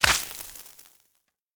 projectile-acid-burn-2.ogg